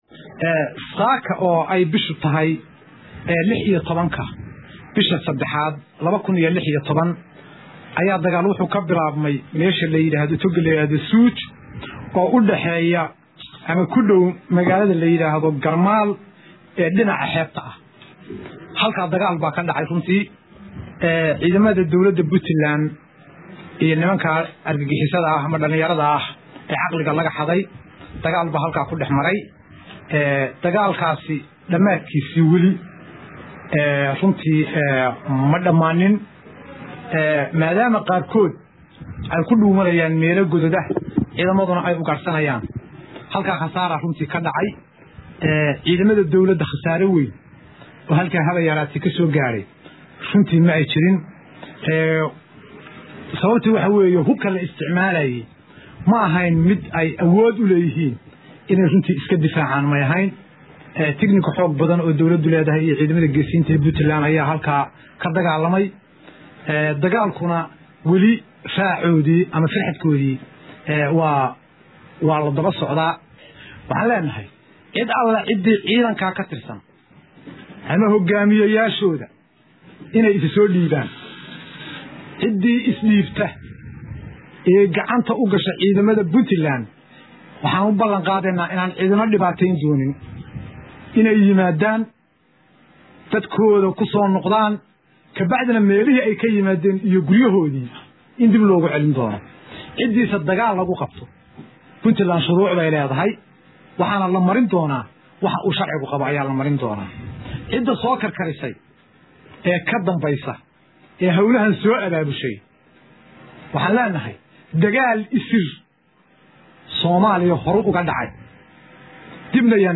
Garowe(INO)- Wasiirka wasaaradda Warfaafinta Puntland oo warbaahinta kula hadlayay magalo Madaxda Garowe, ayaa ka hadlay dagaalka ka Dhacay deegaanka Suuj oo hoostaga degamada Dangoroyo ee gobolka Nugal.